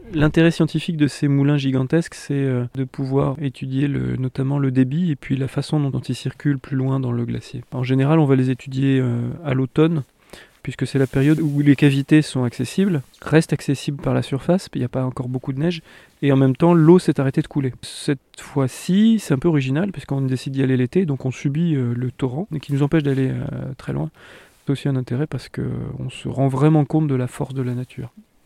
TEMOIGNAGE